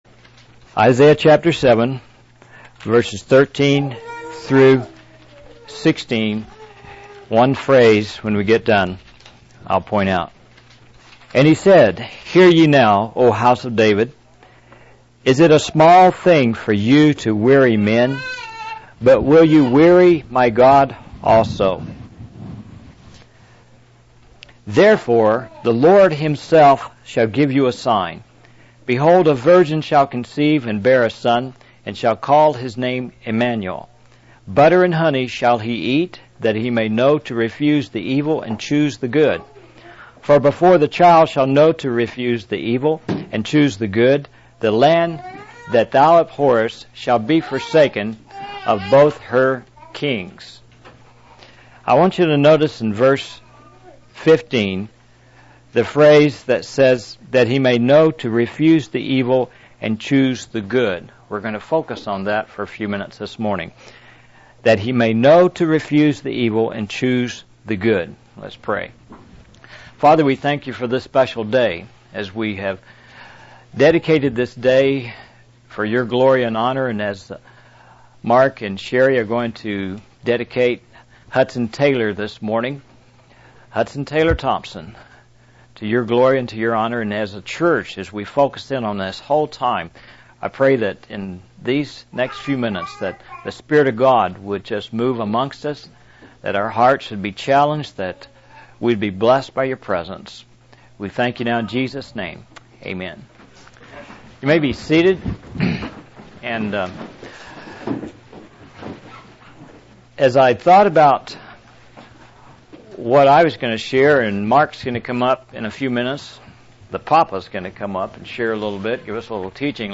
In this sermon, the speaker emphasizes the importance of understanding and mastering our will.